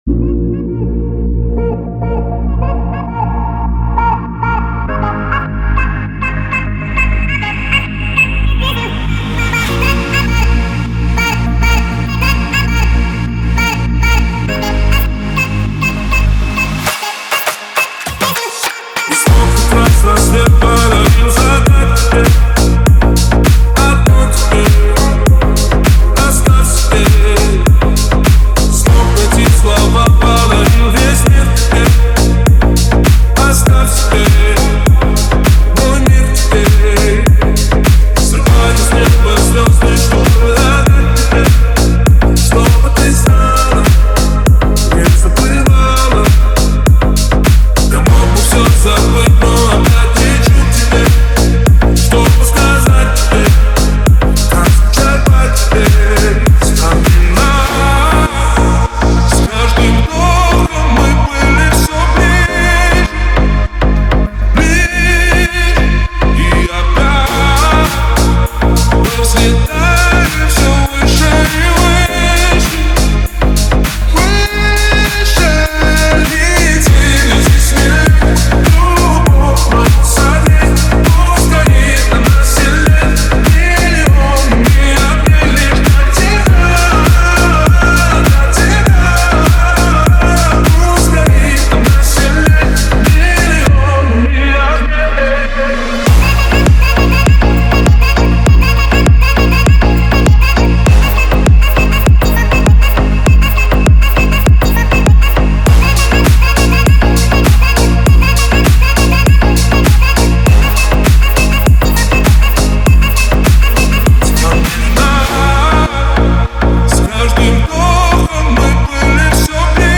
клубные песни